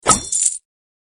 Sound_GetCoins.mp3